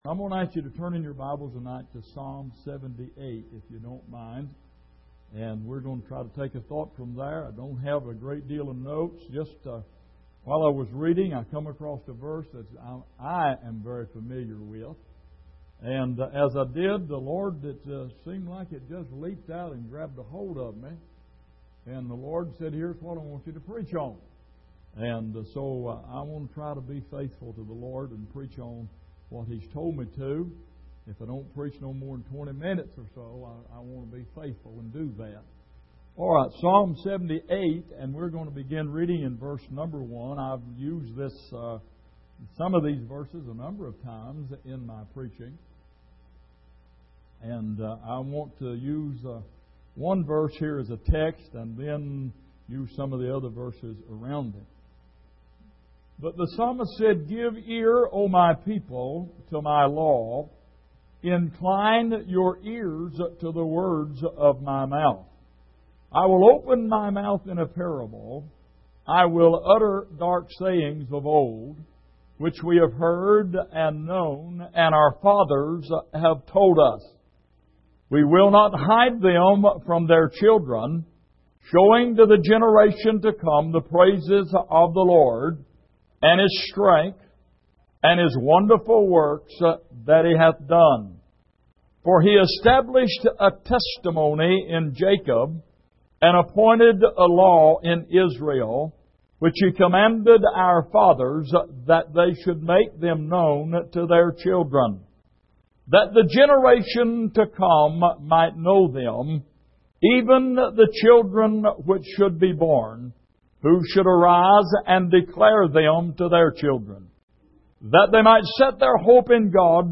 Passage: Psalm 78:1-17 Service: Midweek